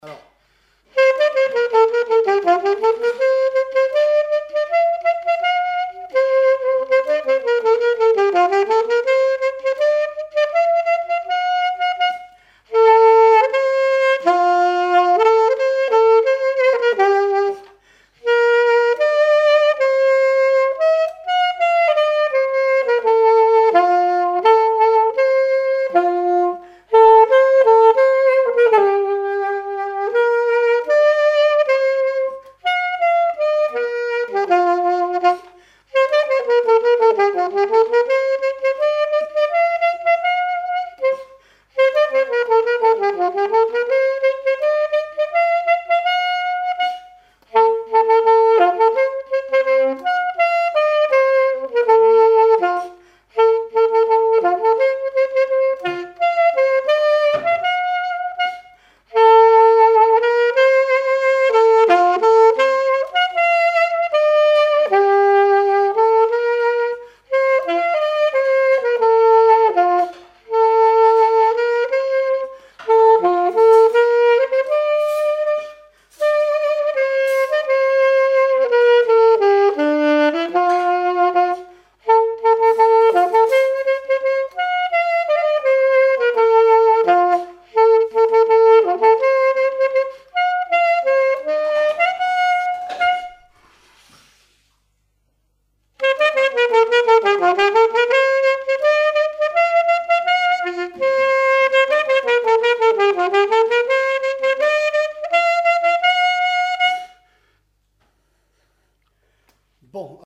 Marches nuptiales n° 1 et 2
Enquête Compagnons d'EthnoDoc - Arexcpo en Vendée
témoignages et instrumentaux
Pièce musicale inédite